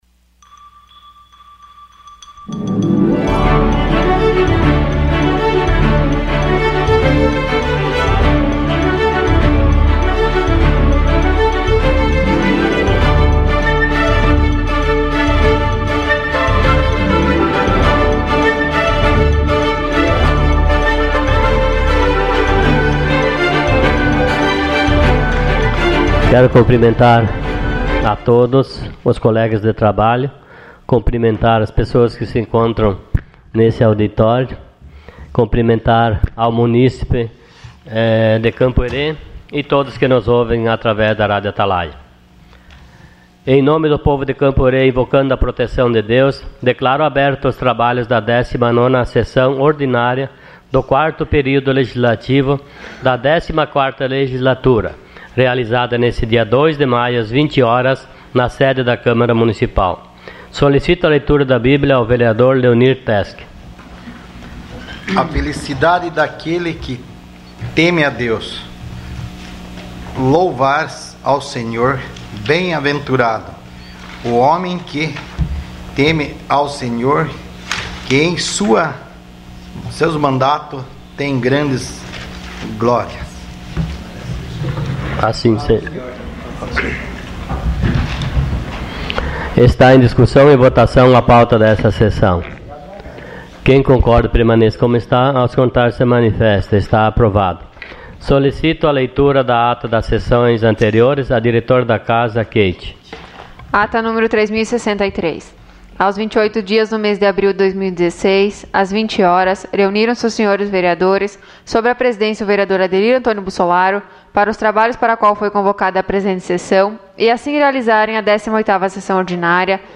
Sessão Ordinária dia 02 de maio de 2016.